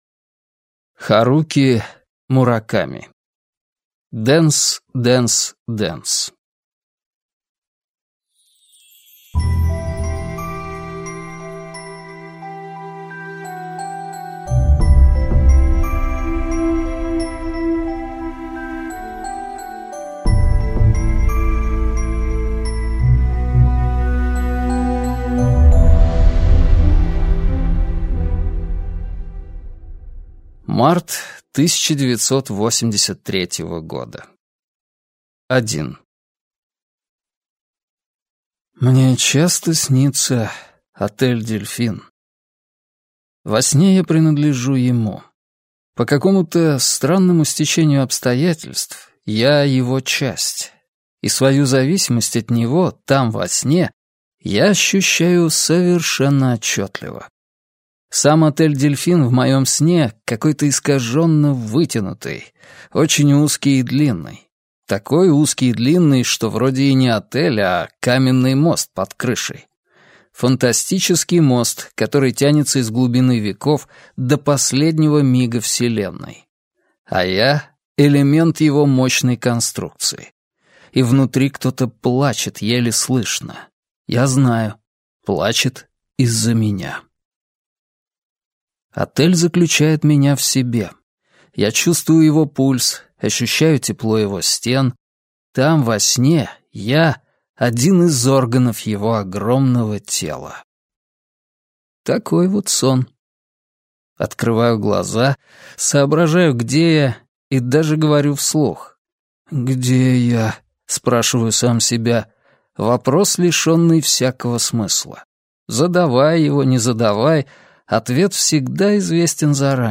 Аудиокнига Дэнс, Дэнс, Дэнс - купить, скачать и слушать онлайн | КнигоПоиск